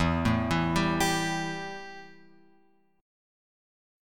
Embb5 chord {0 0 2 0 x 3} chord